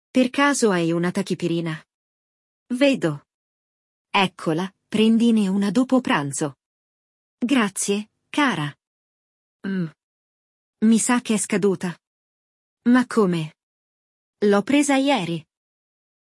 È arrivata una nuovissima puntata del Walk ‘n’ Talk Level Up versione italiana, oggi ascolteremo la conversazione tra Giada e il suo amico Carlo, che le chiede una Tachipirina!
IL DIALOGO